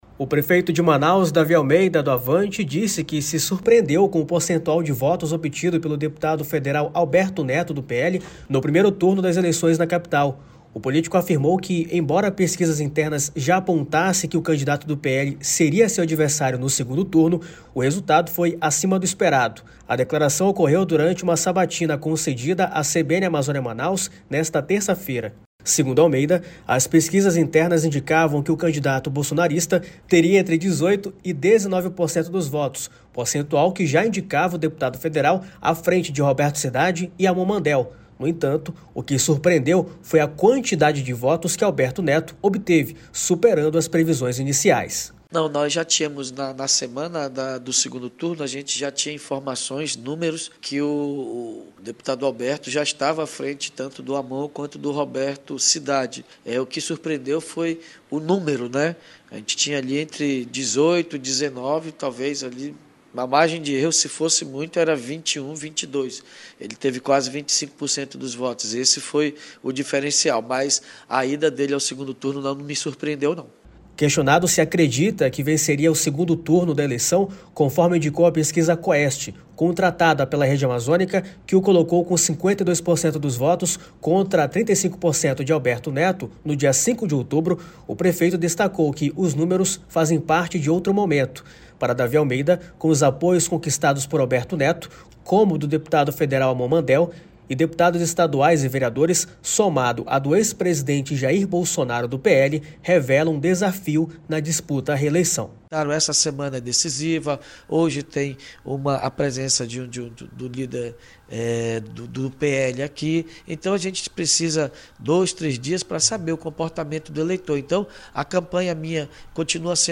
A declaração ocorreu durante uma sabatina concedida à CBN Amazônia Manaus nesta terça-feira, 15.